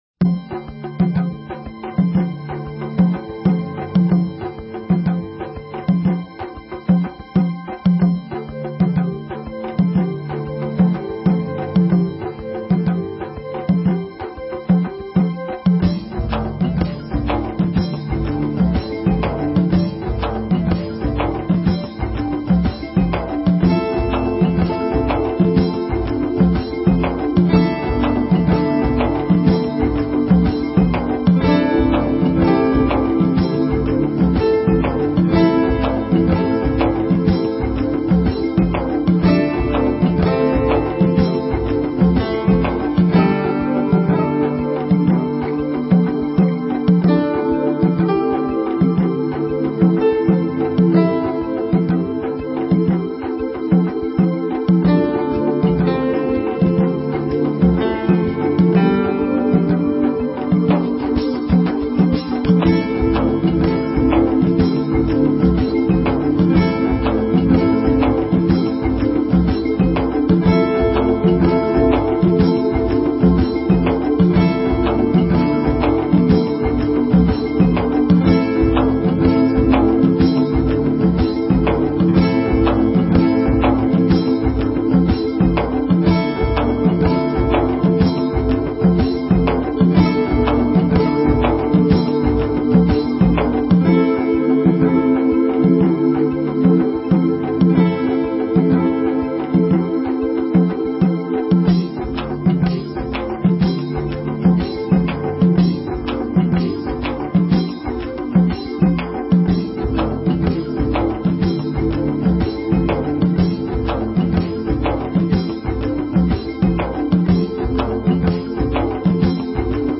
Talk Show Episode, Audio Podcast, DreamPath and Courtesy of BBS Radio on , show guests , about , categorized as
From the sublime to the ridiculous and everything in between. Comedy, music, and a continuous weaving of interviews and story telling (DreamPath style) covering numerous alternative thought topics.